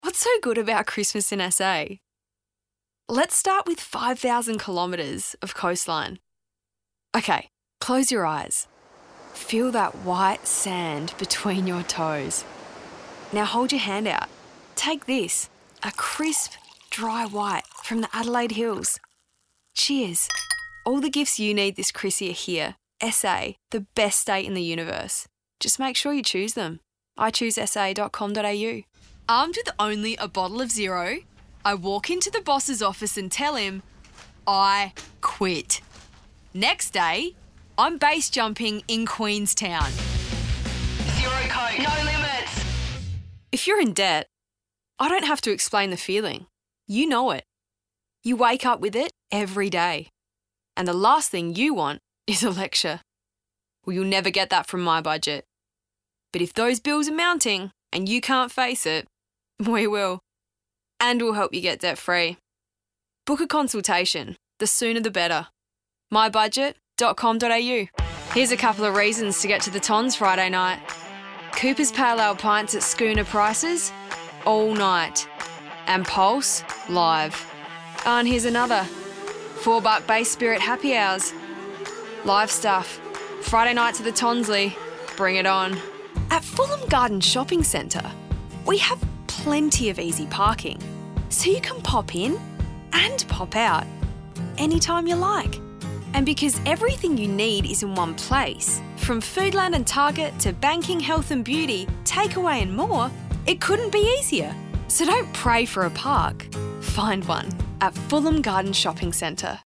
Voice Over Work
Sometimes I like to get in the booth.